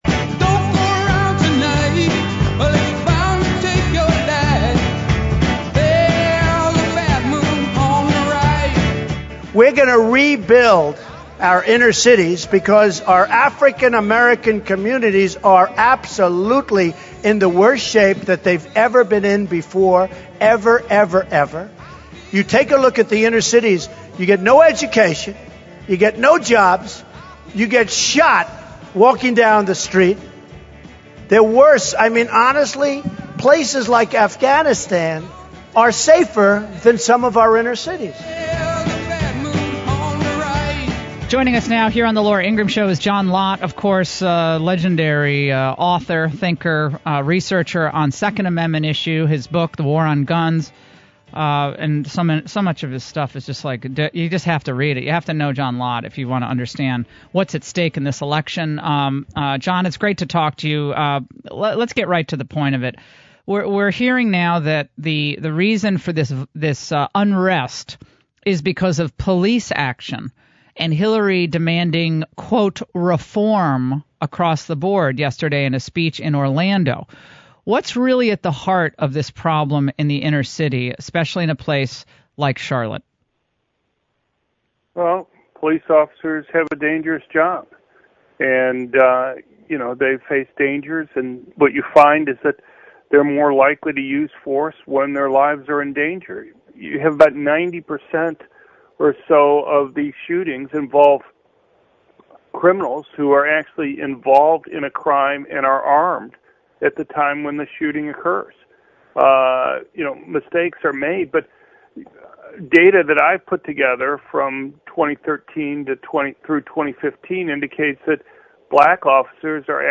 Dr. John Lott talked to Laura Ingraham for two segments of her show on Thursday: in the first segment they discussed the riots in North Carolina over the police shooting and in the second segment starting at 8:50 into the audio below they discussed the threat that Hillary Clinton poses to people being able to have guns for self-protection.